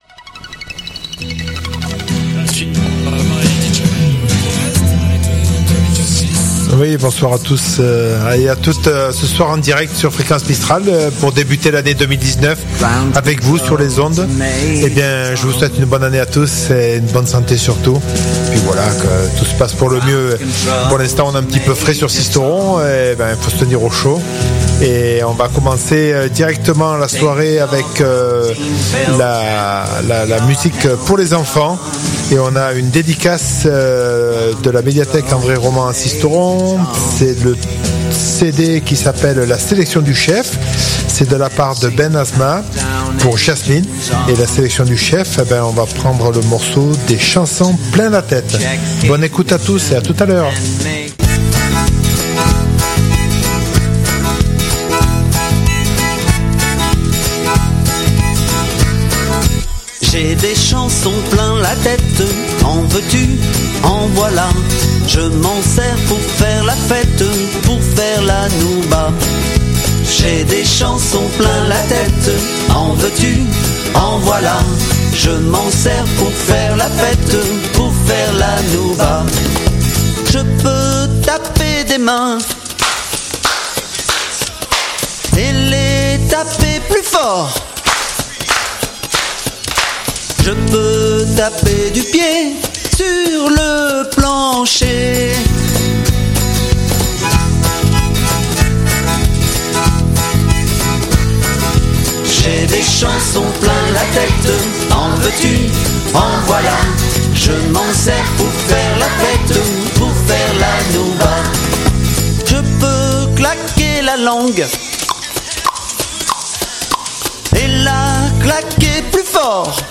Le rendez-vous incontournable tous les premiers mardi du mois sur le 99.2, ça continue en 2019 !
Le résultat : une programmation exceptionnelle avec des grands morceaux à écouter sur Fréquence Mistral, des nouveautés et plus encore.